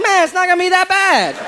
Category: Comedians   Right: Both Personal and Commercial
Tags: aziz ansari aziz ansari comedian